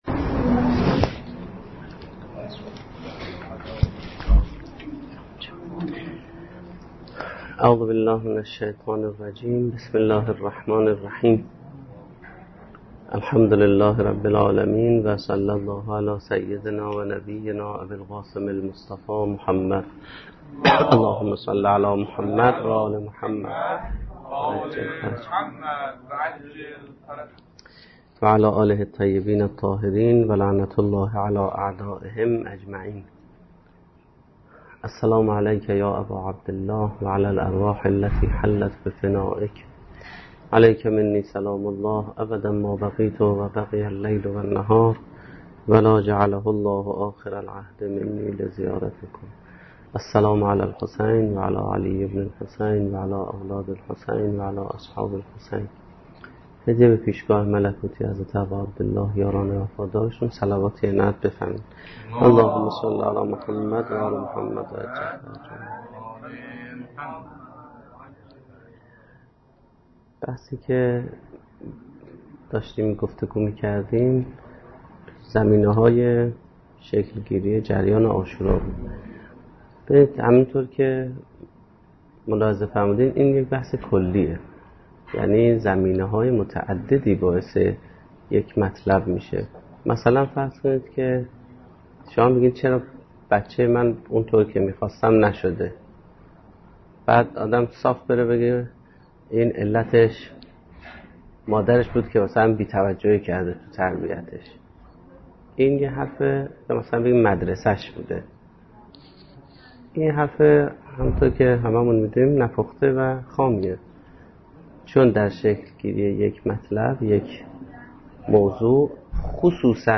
سخنرانی
ریشه های سیاسی حادثه عاشورا - شب دوم محرم الحرام 1436